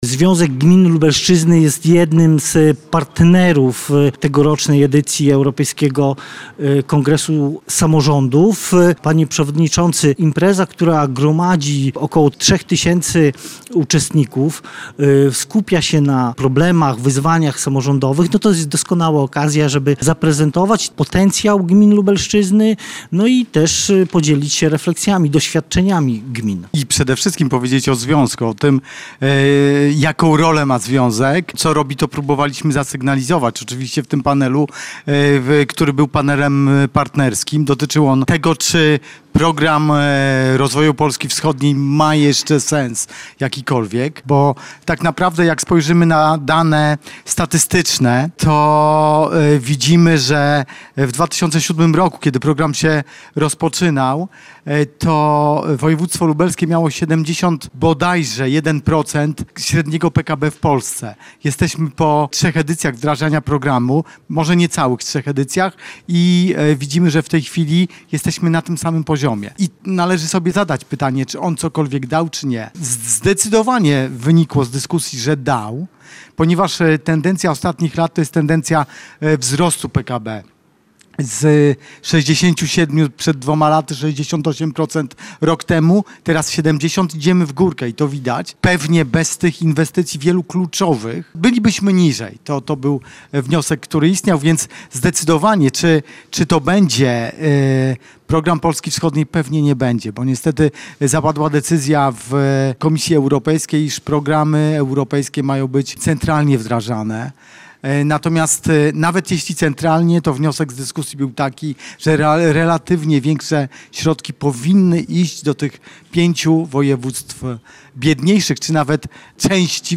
Organizacja jest partnerem trwającego w Mikołajkach 11. Europejskiego Kongresu Samorządów.